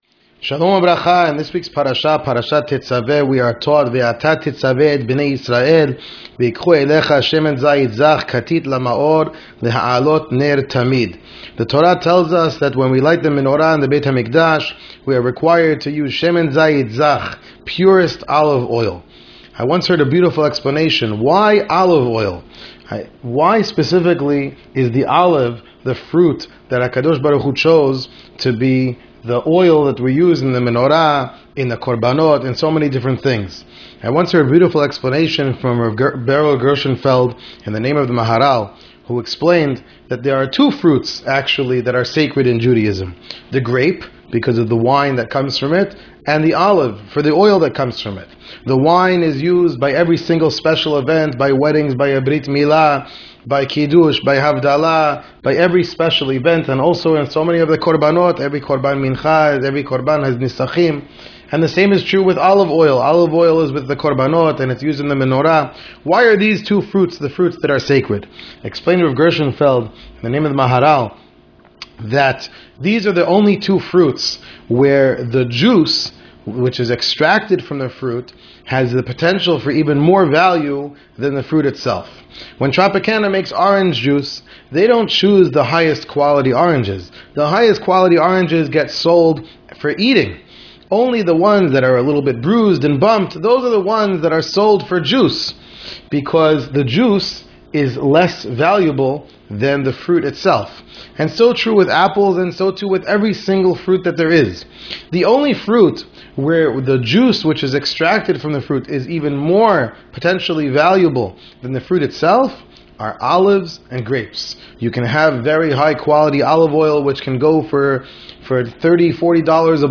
An Audio Shiur